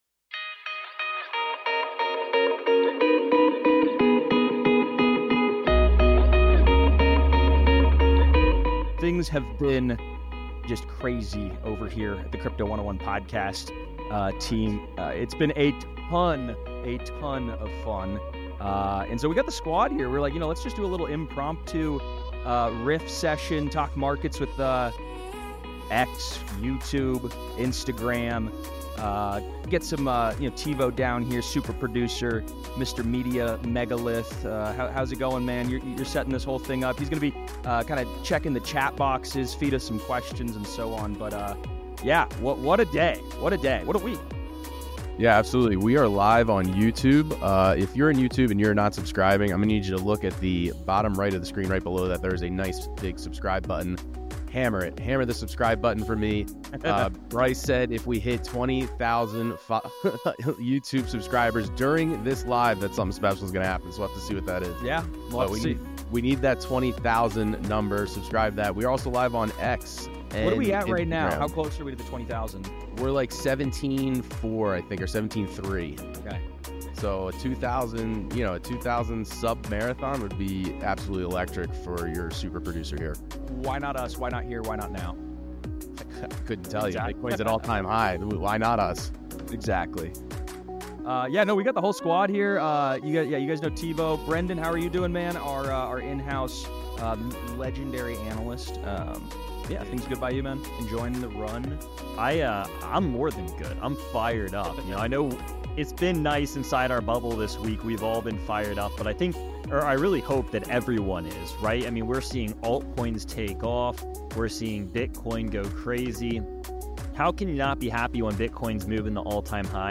This is the audio version of a YouTube Livestream we recored on FRIDAY NOVEMBER 8th as Bitcoin was hitting ALL TIME HIGHS. There is a lot of great info around Bitcoin, Altcoins, and MEME COINS!